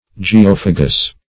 Geophagous \Ge*oph"a*gous\, a.